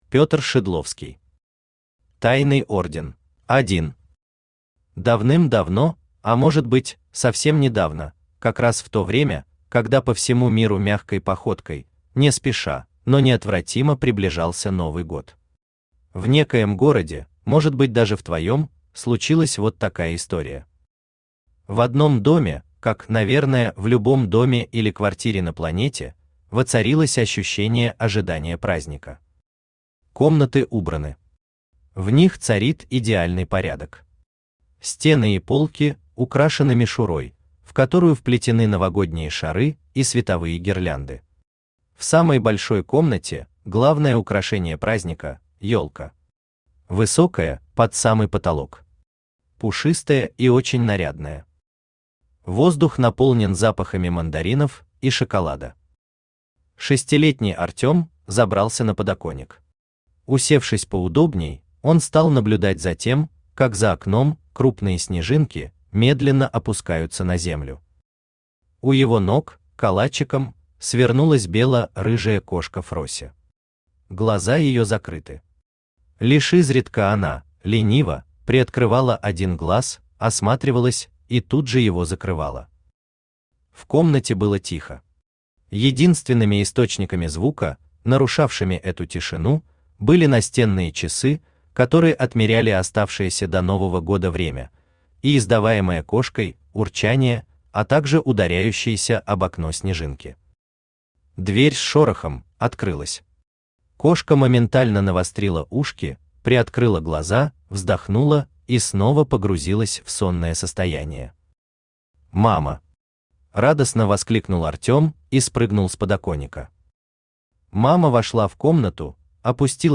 Аудиокнига Тайный орден | Библиотека аудиокниг
Aудиокнига Тайный орден Автор Петр Васильевич Шидловский Читает аудиокнигу Авточтец ЛитРес.